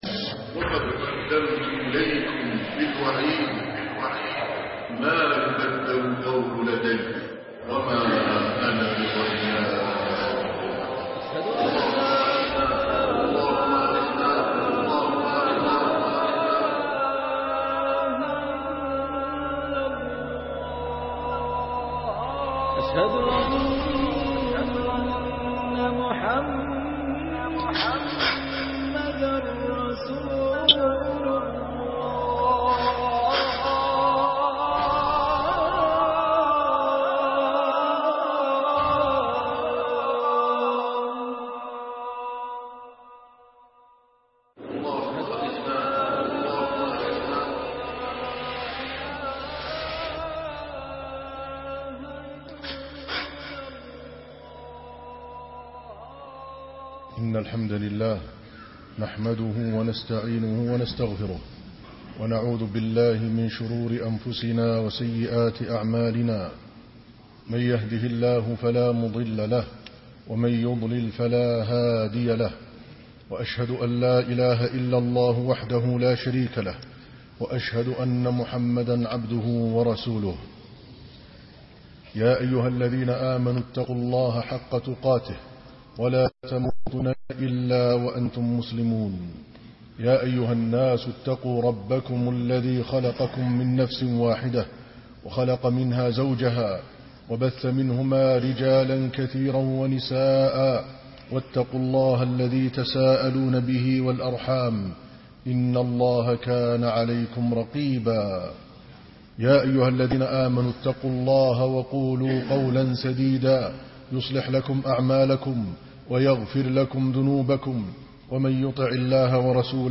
ذم الغيبة( 27/2/2014) خطب منبرية - الشيخ عادل الكلباني